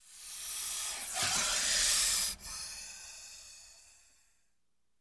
the-sound-of-hissing-snakes